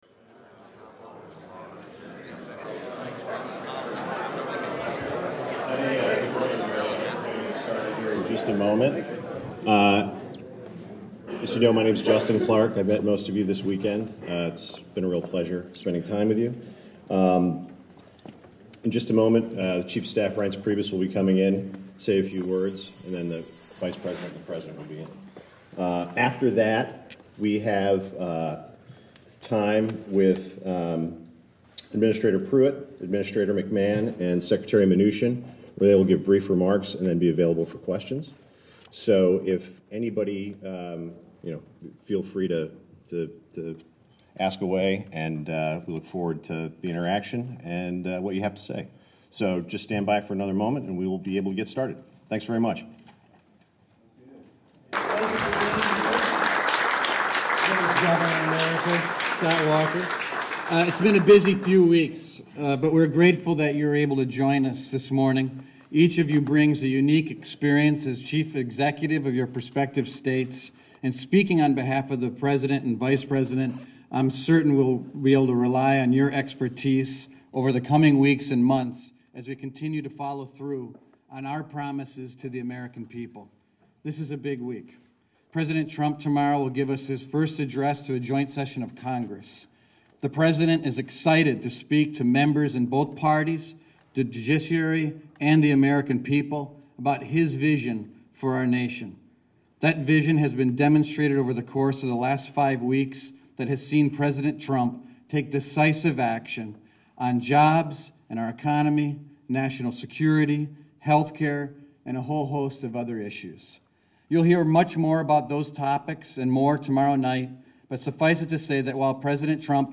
U.S President Donald Trump speaks at the National Governors Association's winter meeting